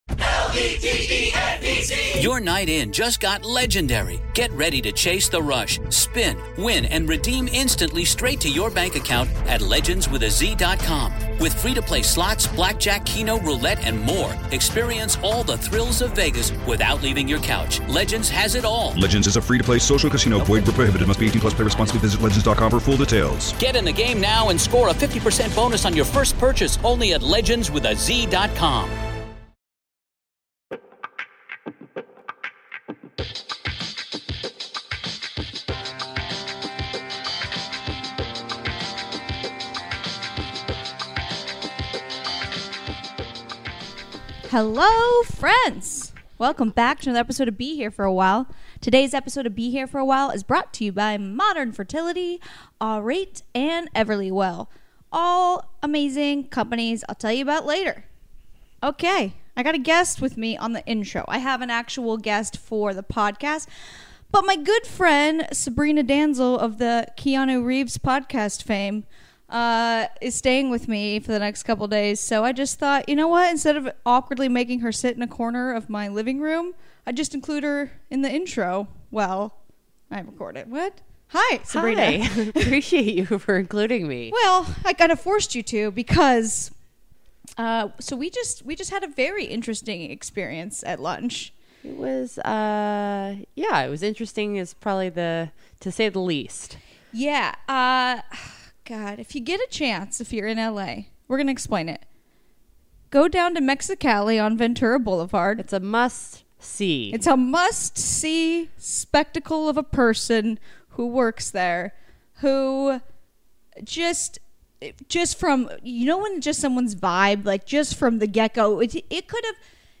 174: Hilarious Chat w German Actor, DJ, Comedian Flula Borg!
Education, Comedy, Comedy Interviews, Self-improvement